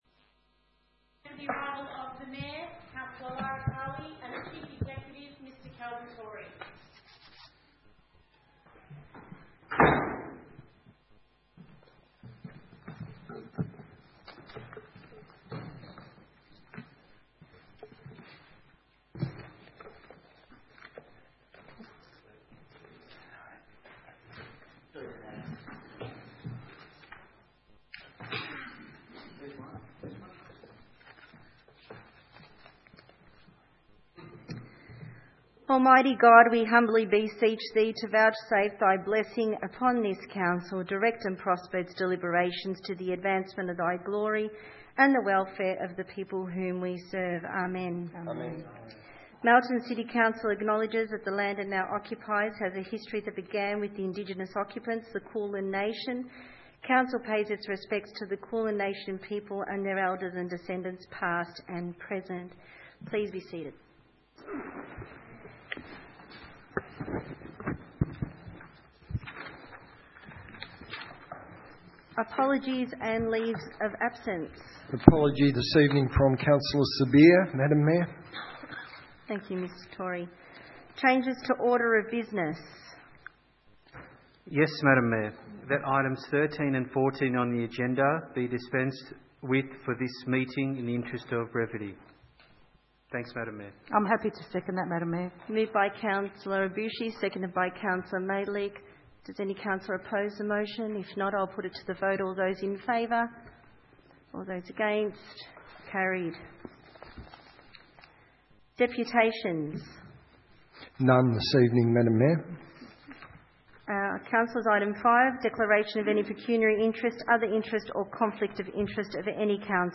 Ordinary Meeting 2 April 2020
Council Chambers, 232 High Street, Melton, 3337 View Map